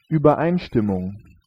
Ääntäminen
IPA : /əˈkɔːd/